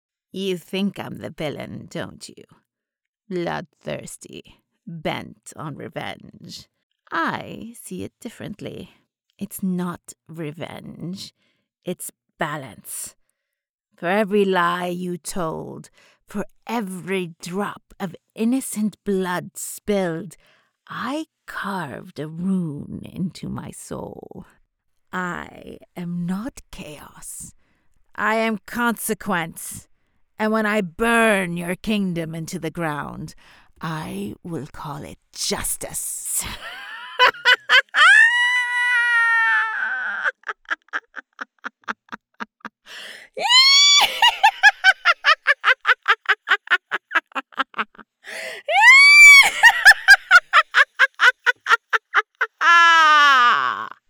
Witch’s laugh (seriously I scare small children)
Character Samples
Witch.mp3